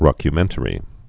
(rŏkyə-mĕntə-rē)